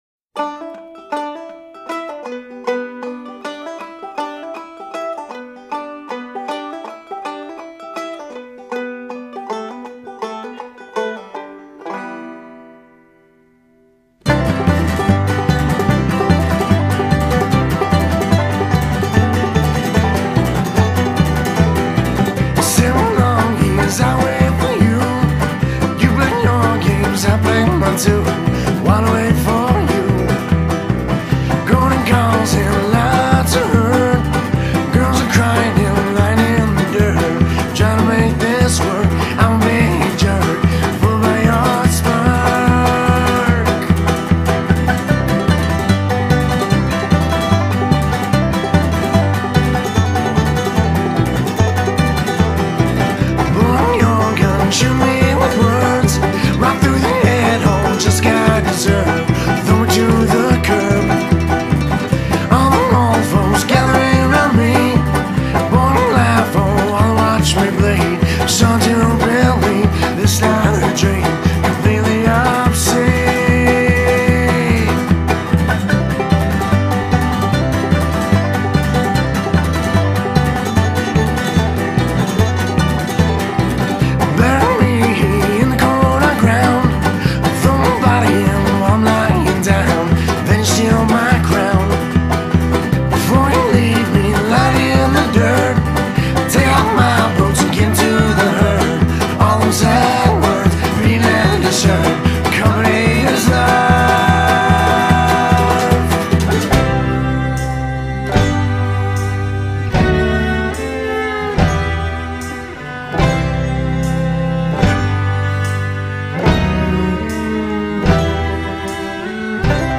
Canadian Folk-Bluegrass musical ensemble
dark Roots music